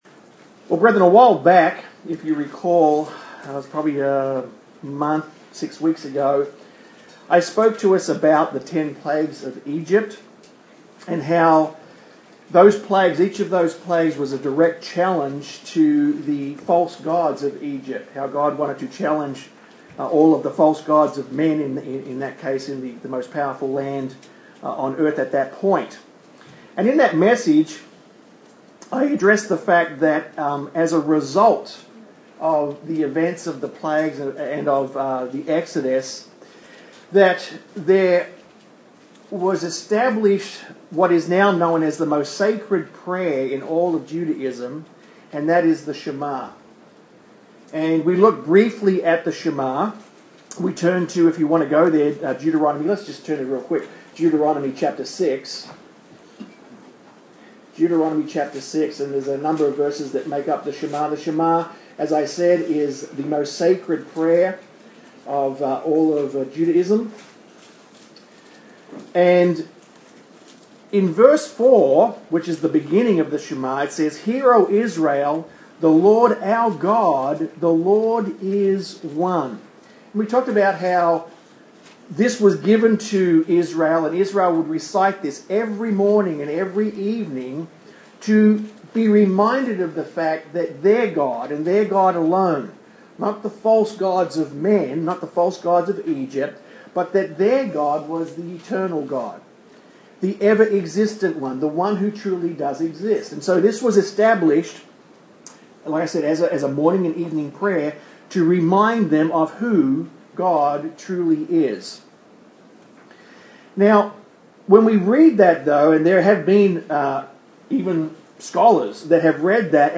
Sermons
Given in Austin, TX